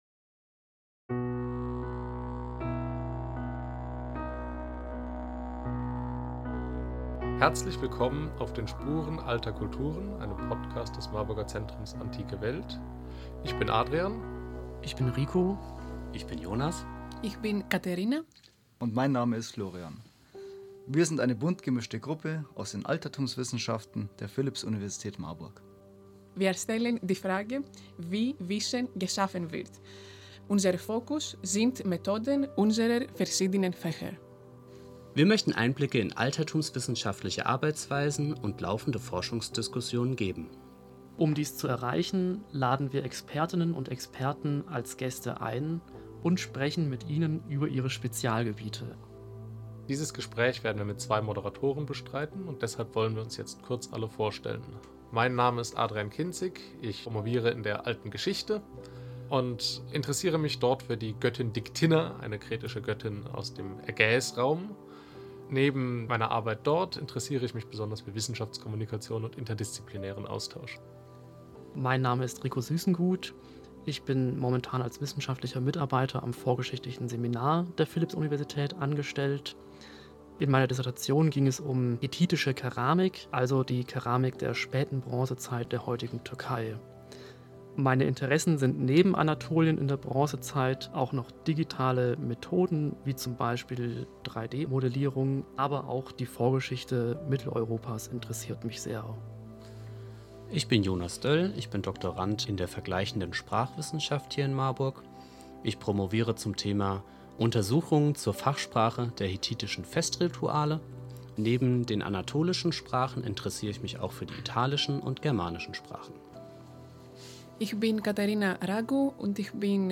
Unsere Redaktionsmitglieder stellen sich kurz vor und erzählen